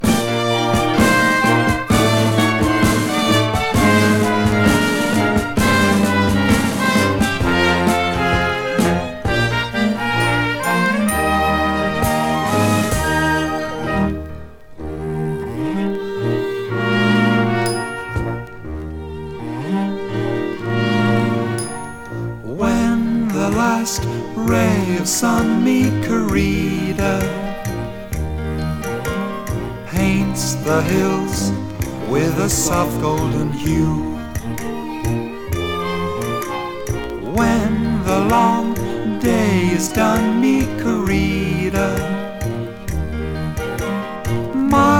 Jazz, Pop, Ragtime, Big Band　USA　12inchレコード　33rpm　Stereo